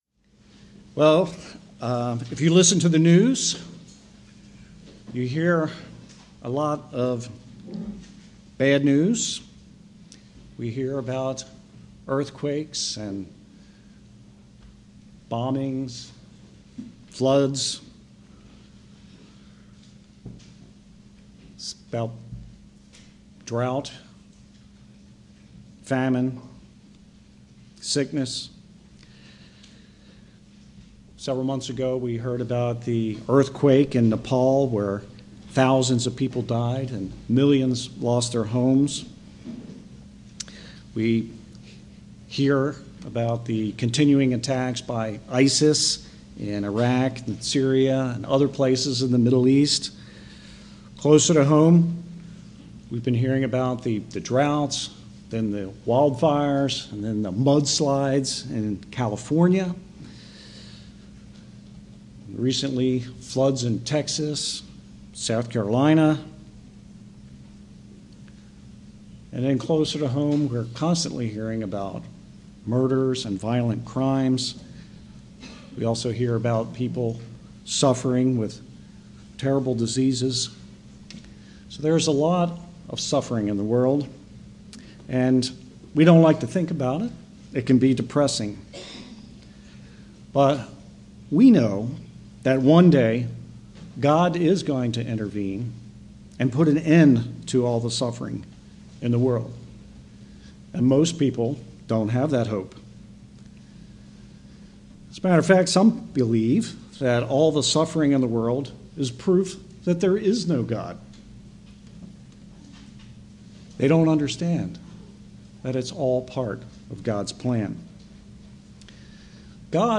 Sermons
Given in Columbia, MD